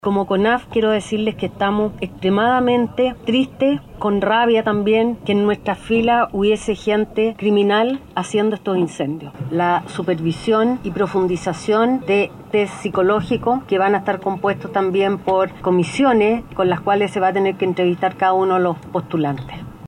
En este mismo sentido, la directora de Conaf, Aída Baldini, señaló que la institución se encuentra muy afectada por la participación de personas pertenecientes a dicha organización en incendios forestales.